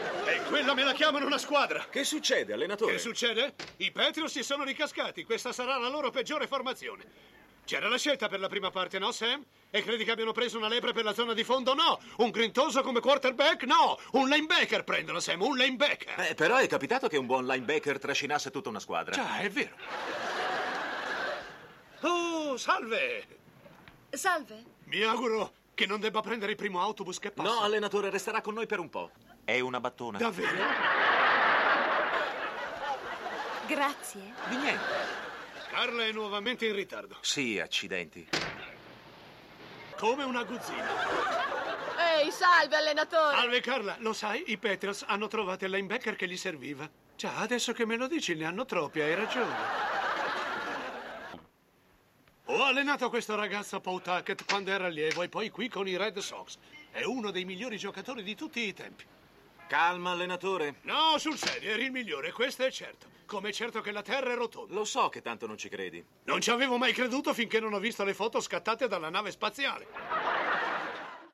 voce di Mimmo Craig nel telefilm "Cheers - Cin Cin", in cui doppia Nicholas Colasanto.